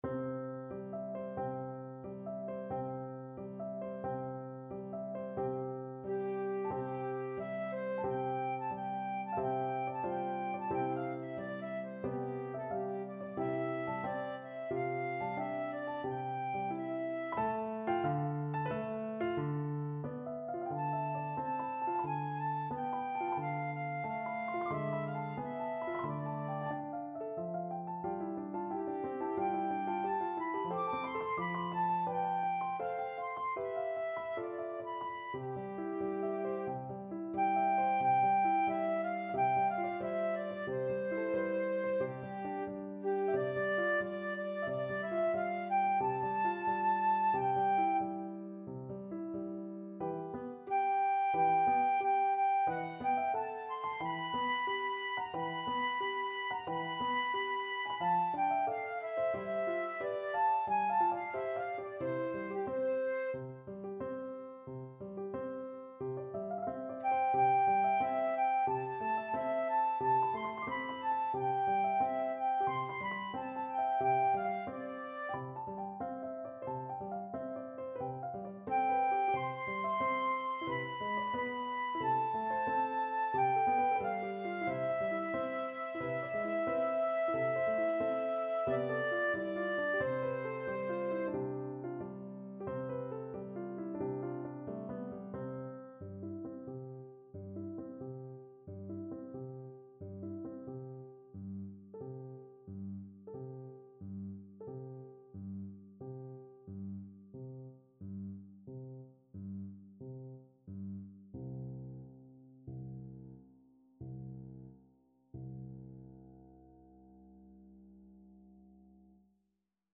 Flute version
4/4 (View more 4/4 Music)
Moderato =90
Classical (View more Classical Flute Music)